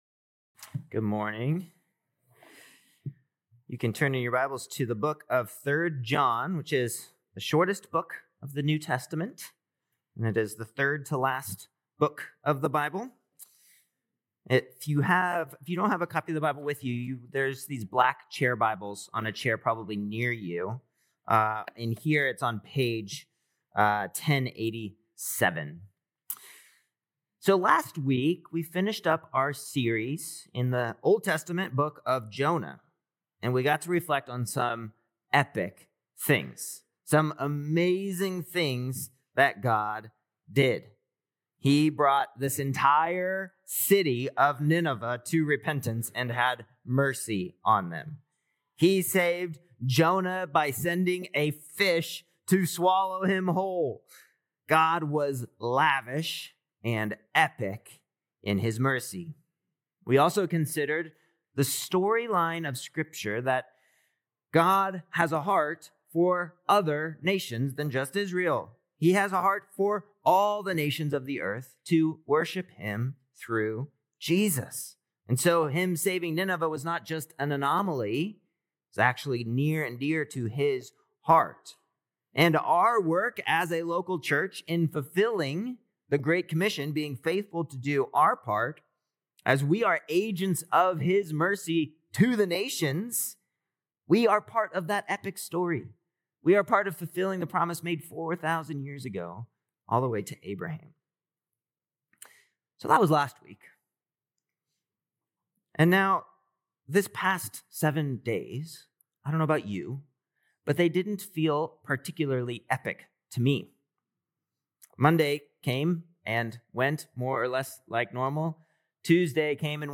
Faith Church Sermon Podcast